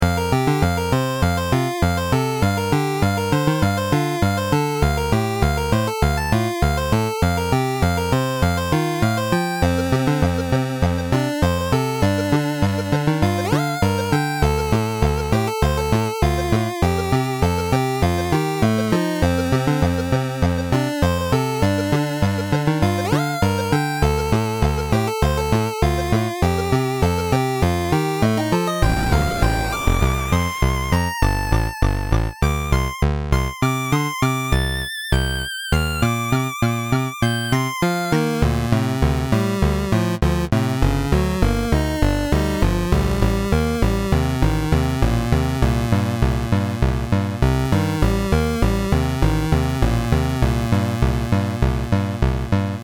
Town theme.